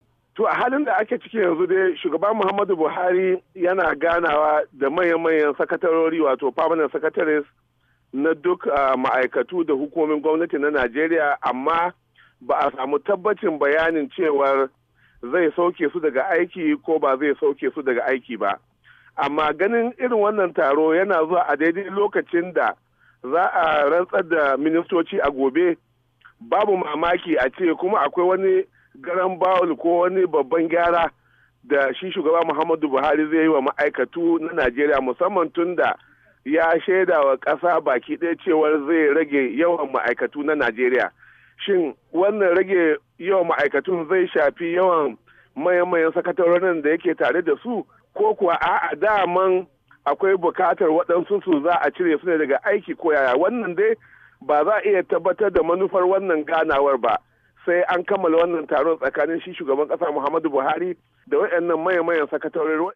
Rahoton sauke Manyan sakatarori a Najeriya-1'02